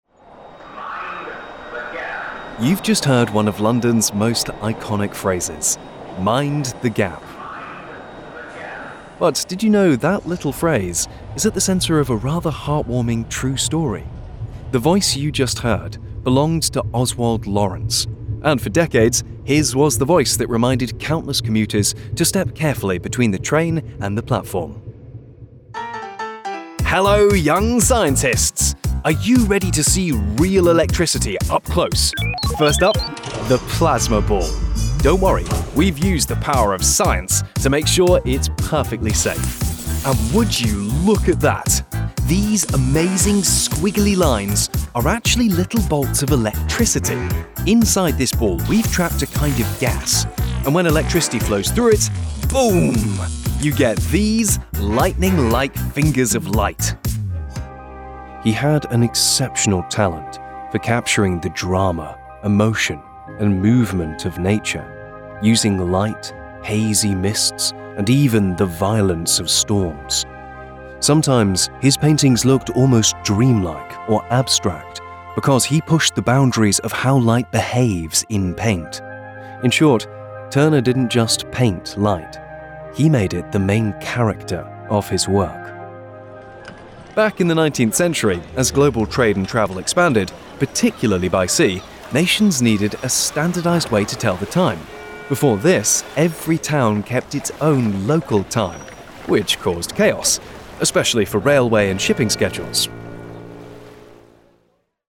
Commerciale, Naturelle, Amicale, Chaude, Polyvalente
Guide audio